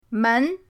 men2.mp3